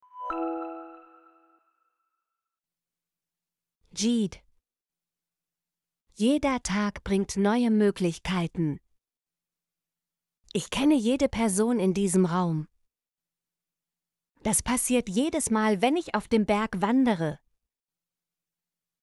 jede - Example Sentences & Pronunciation, German Frequency List